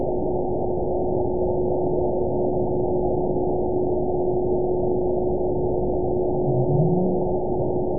event 920513 date 03/28/24 time 15:38:18 GMT (1 year, 1 month ago) score 9.34 location TSS-AB02 detected by nrw target species NRW annotations +NRW Spectrogram: Frequency (kHz) vs. Time (s) audio not available .wav